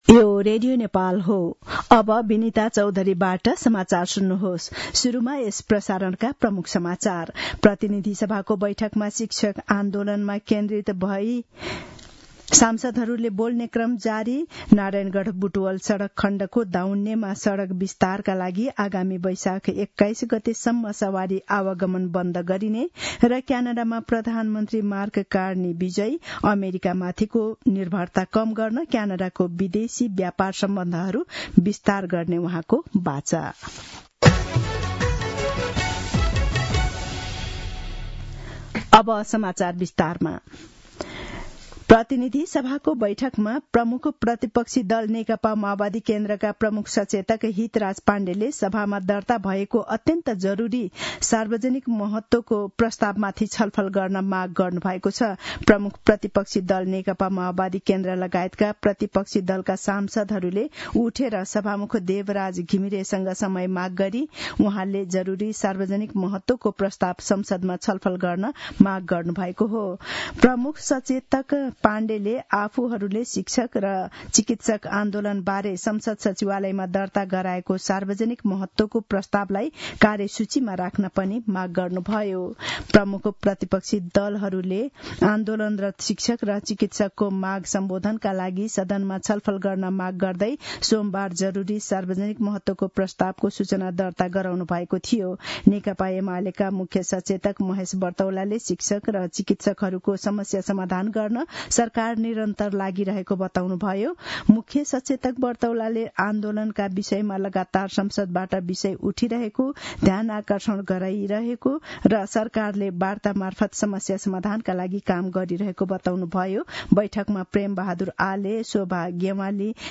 दिउँसो ३ बजेको नेपाली समाचार : १६ वैशाख , २०८२